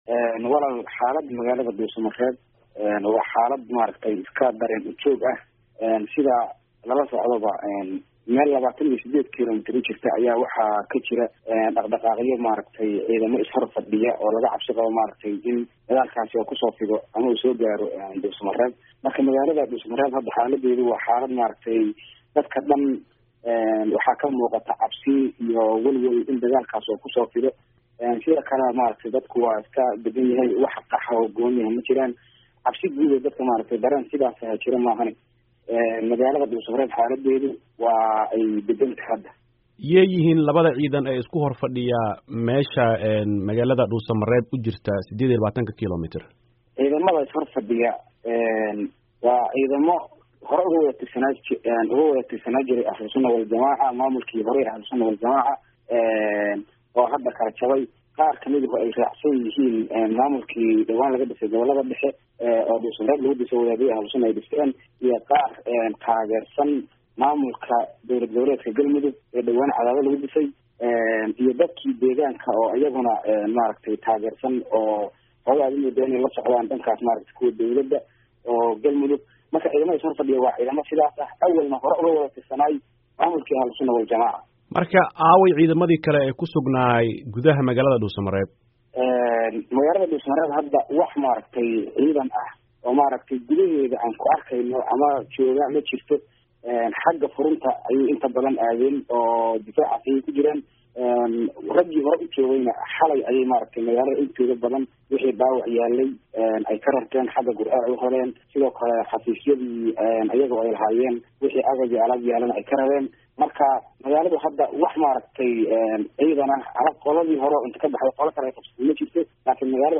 Wareysi: Dhuusomareeb